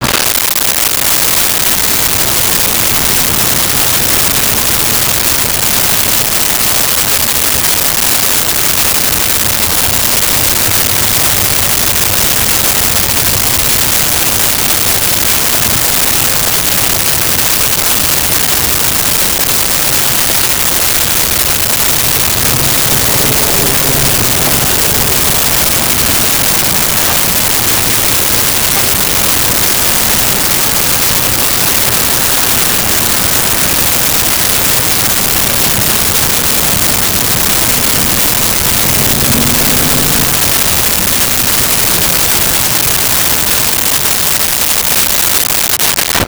Big City Streets.wav